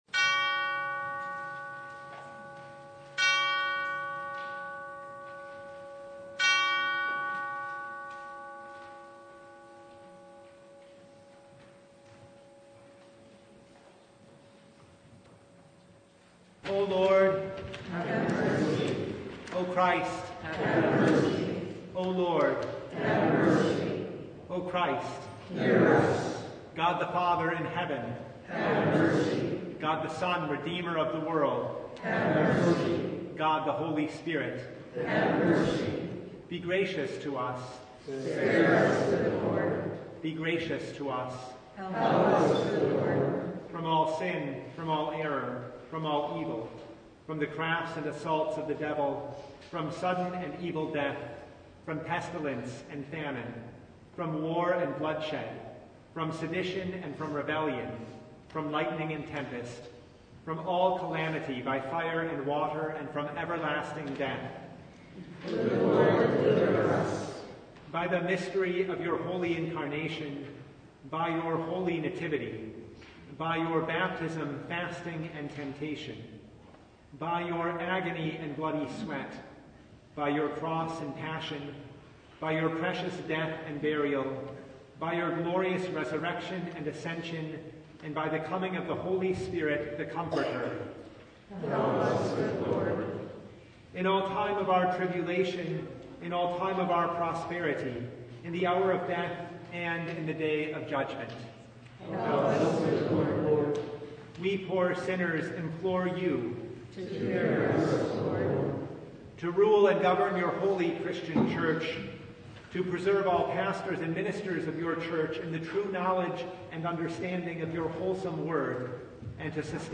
Passage: Exodus 8:1-32 Service Type: Lent Midweek Noon
Topics: Full Service